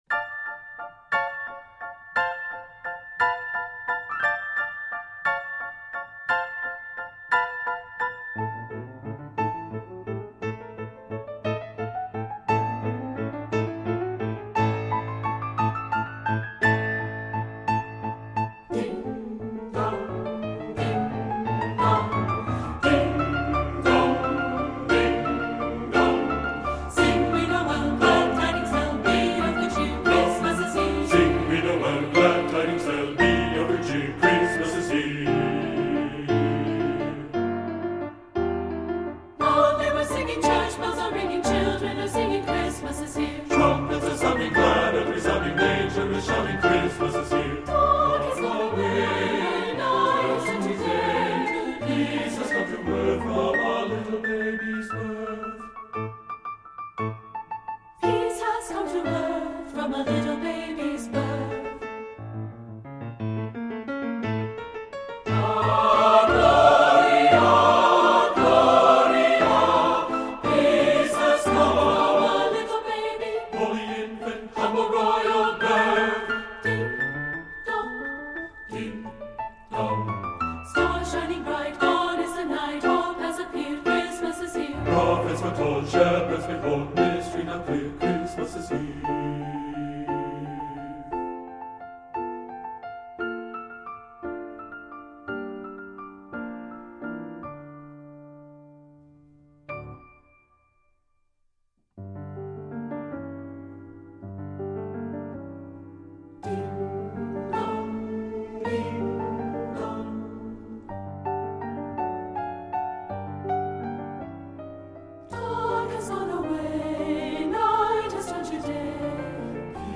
Voicing: SAB and Piano 4 Hands